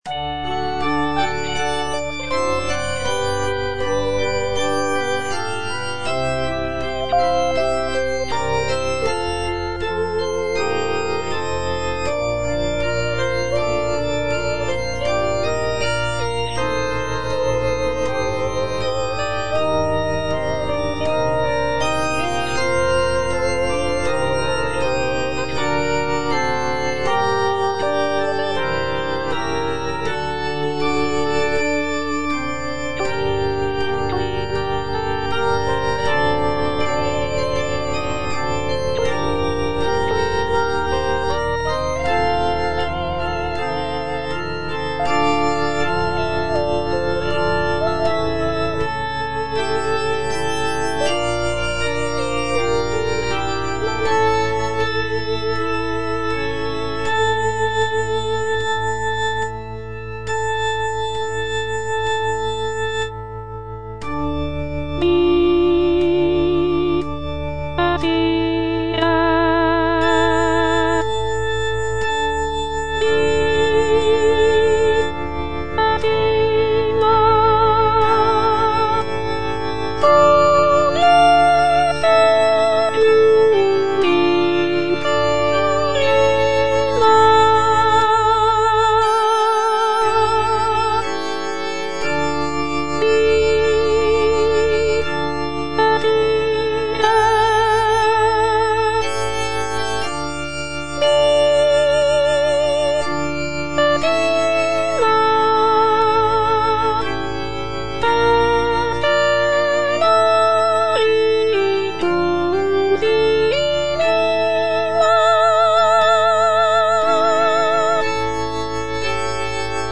Soprano (Voice with metronome) Ads stop
is a sacred choral work rooted in his Christian faith.